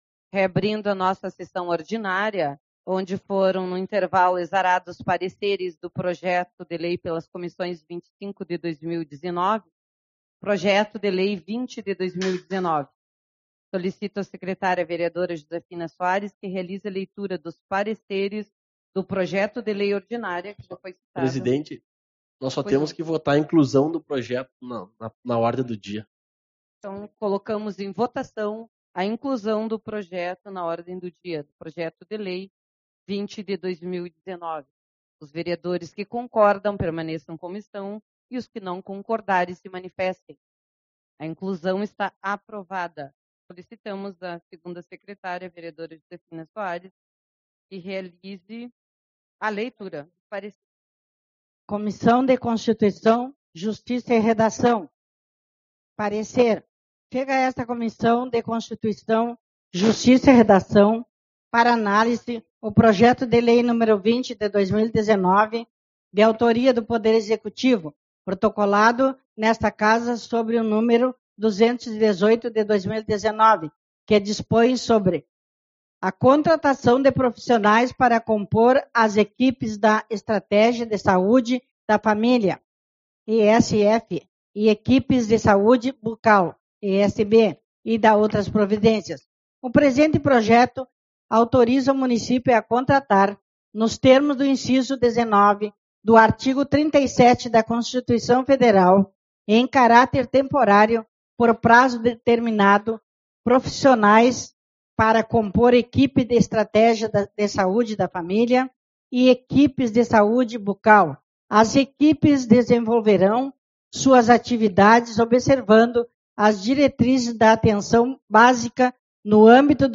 16/04 - Reunião Ordinária